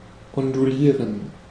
Ääntäminen
US : IPA : [ˈweɪv]